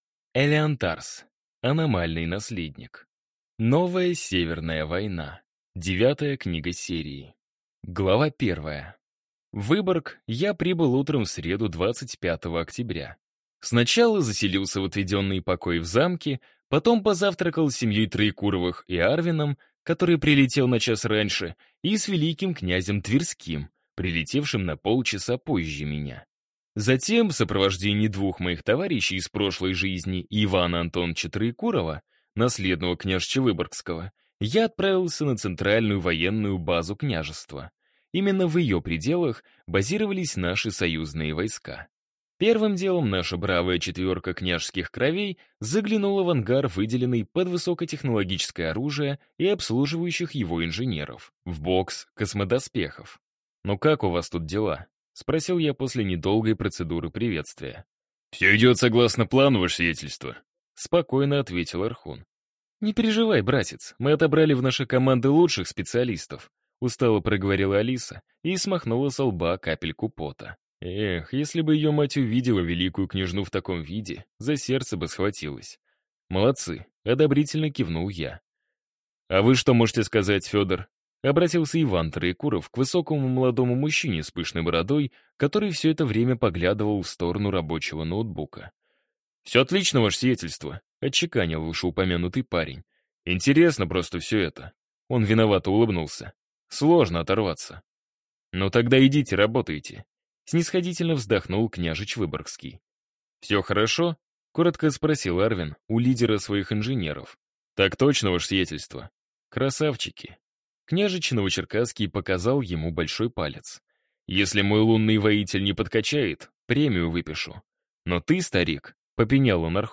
Аудиокнига Аномальный Наследник. Новая Северная Война | Библиотека аудиокниг